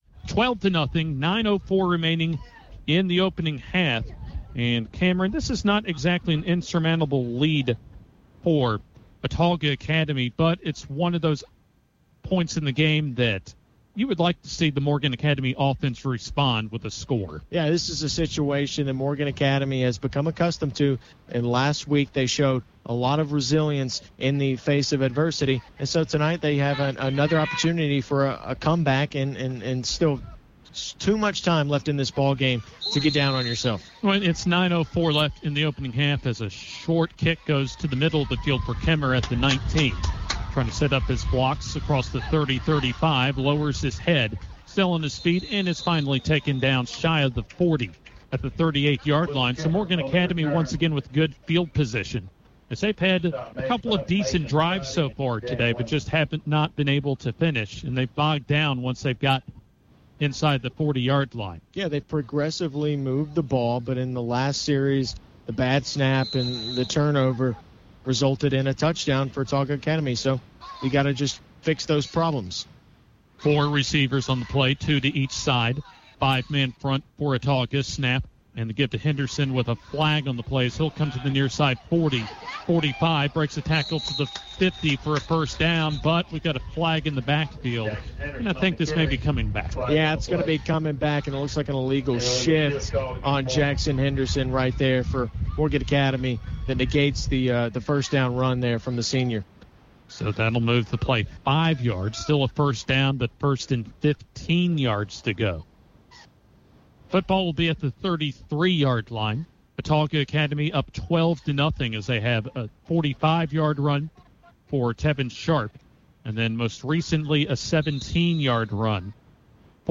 Football Play-by-Play
Morgan Academy vs Autauga Academy Extended PBP Cut.mp3